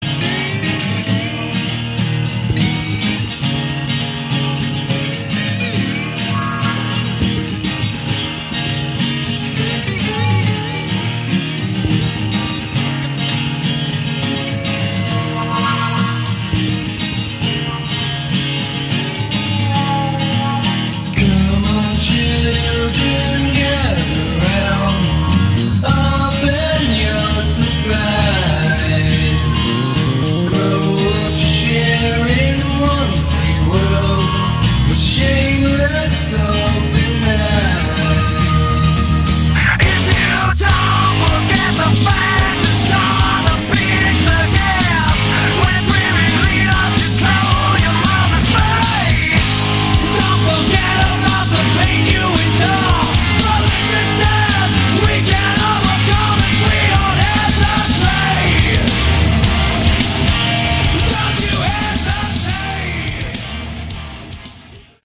Category: Glam/Hard Rock